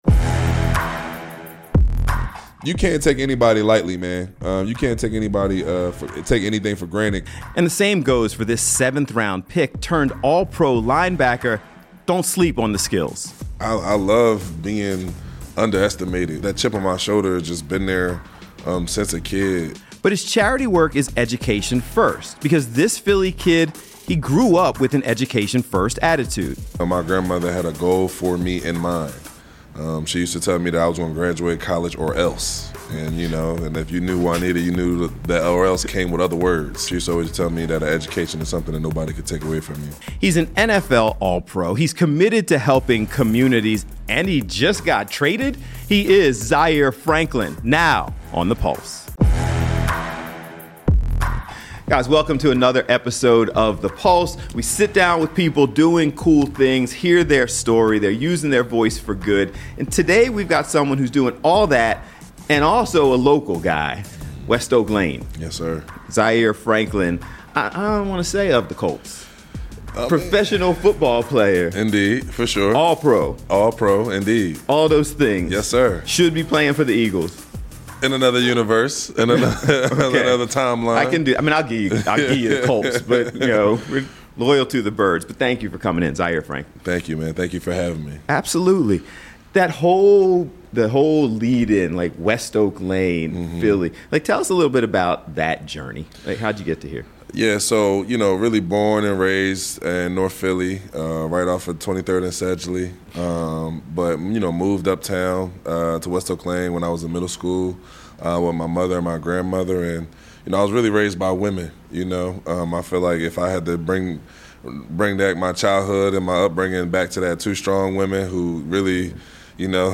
NFL Player and Philly Native Zaire Franklin joins The Pulse to talk about his work on and off the field.